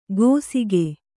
♪ gōsige